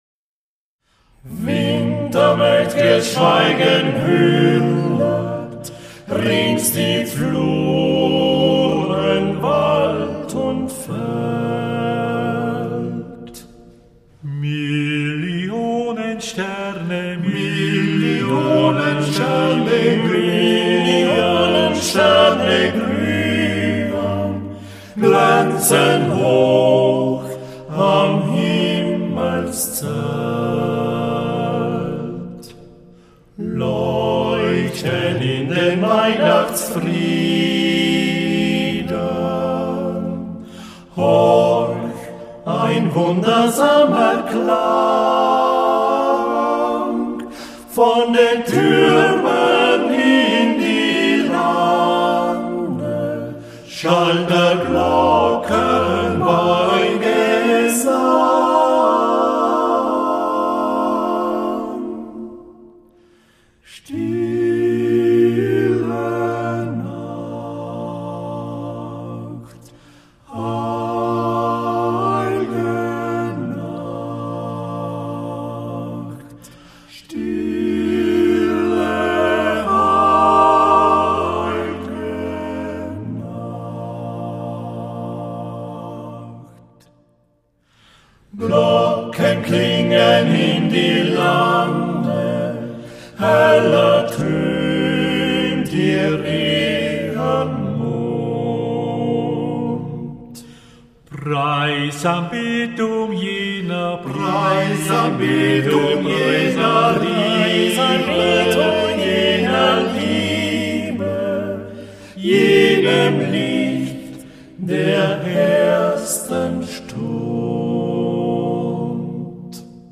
Volksmusik
„A capella“ gesungen